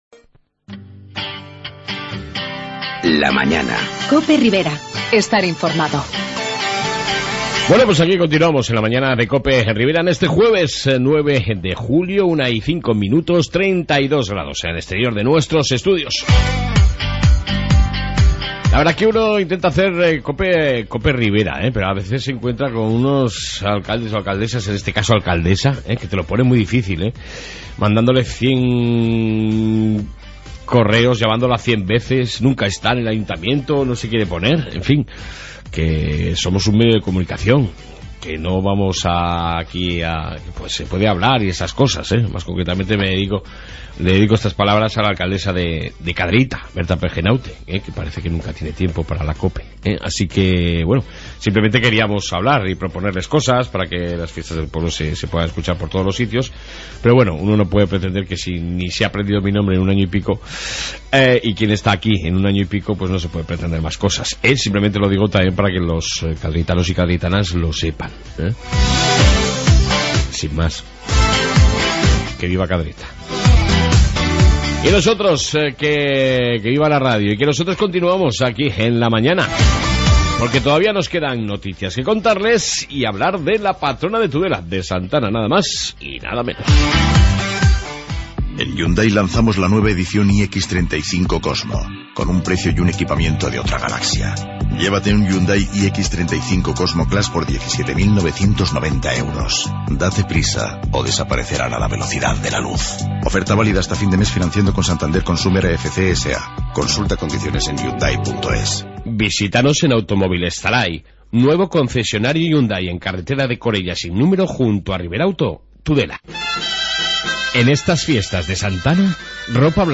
AUDIO: Noticias Riberas y entrevista Congregación de Santa Ana